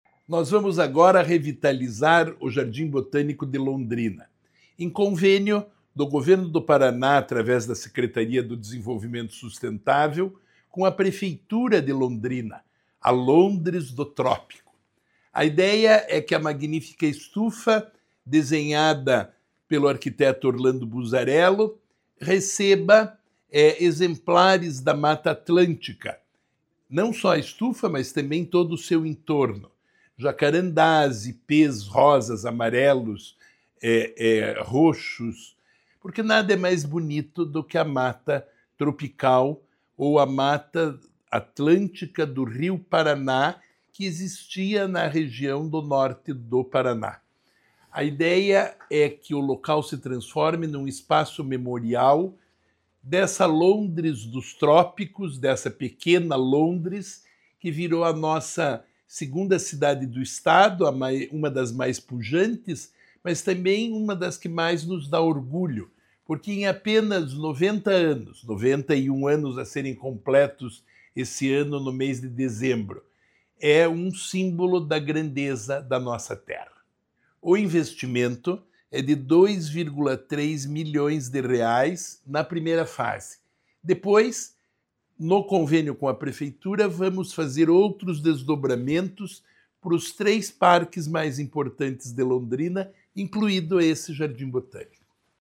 Sonora do secretário do Desenvolvimento Sustentável, Rafael Greca, sobre a revitalização do Jardim Botânico de Londrina